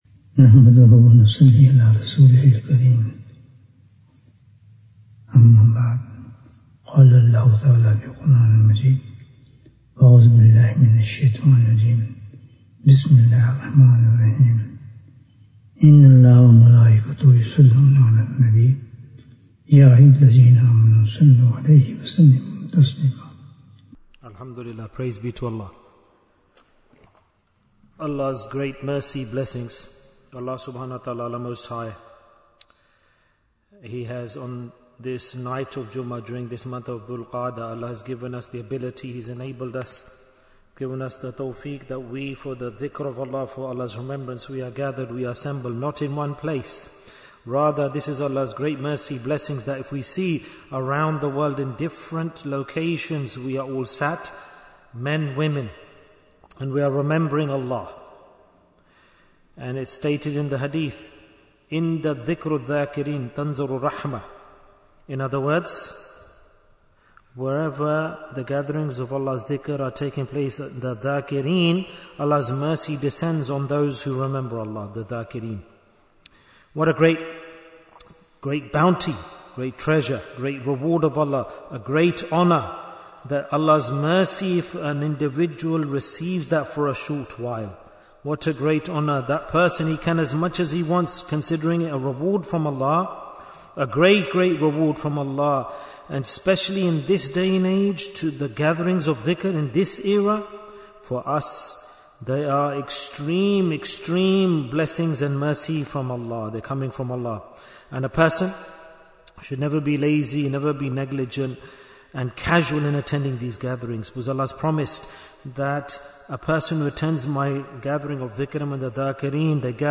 The Benefits of Dhikr Bayan, 8 minutes22nd May, 2025